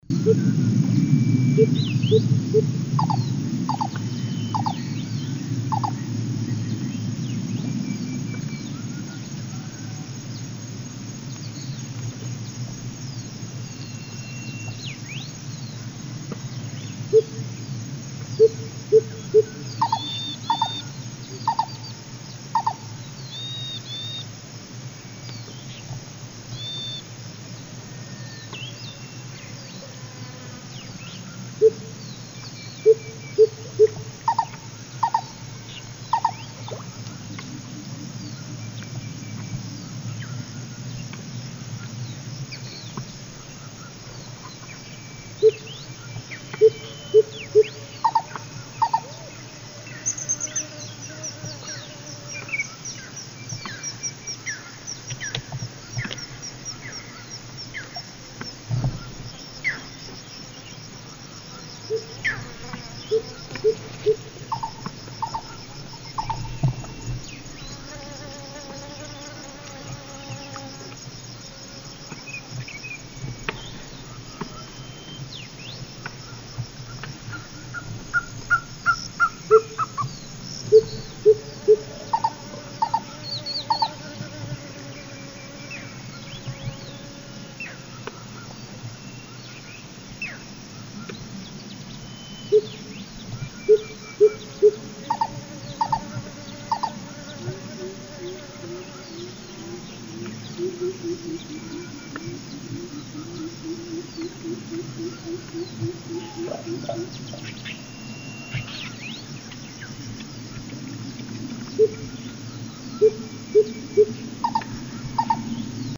Coucal rufin ( Centropus bengalensis ) ssp lignator
Chant et cris enregistrés le 18 juillet 2012, en Chine, province du Guangxi, à Shanli près de la ville de Beishan.